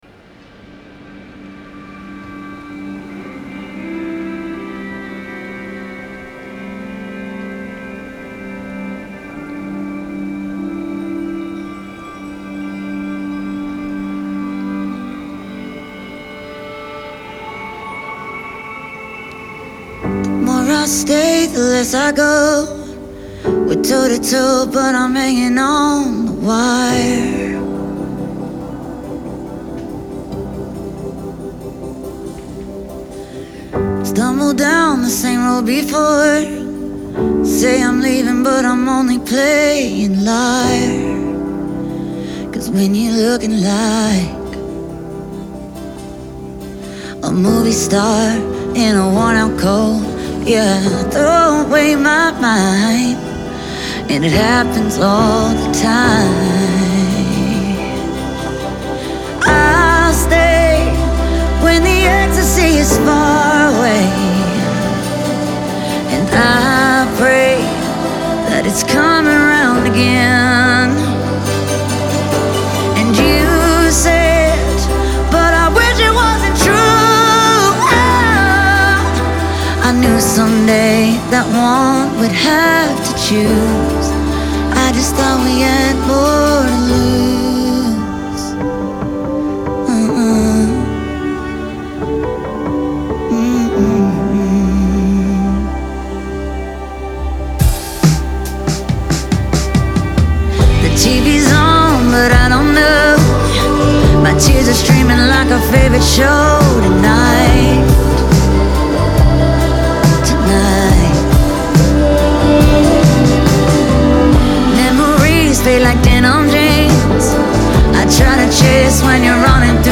• Жанр: Pop, Alternative